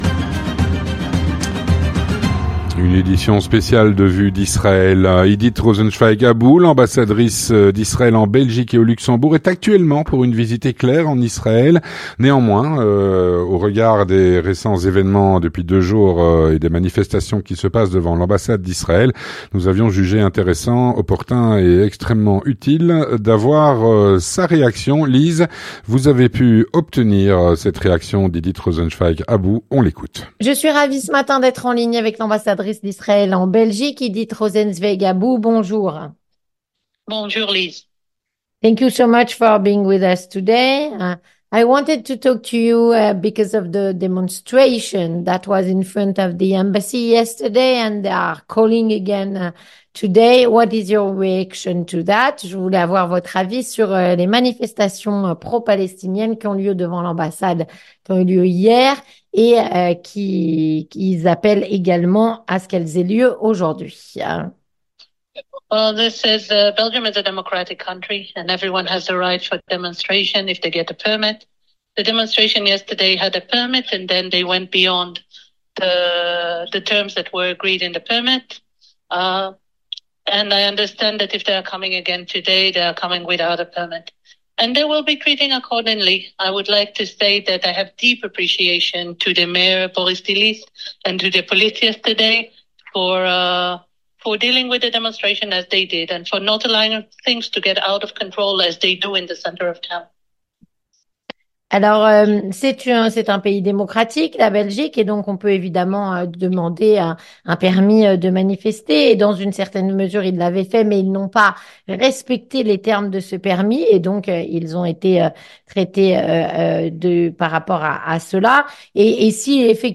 Avec S.E. Idit Rosenzweig-Abu, ambassadrice d'Israël en Belgique et au Grand-Duché du luxembourg, actuellement en visite éclair en Israël, qui réagit à notre micro.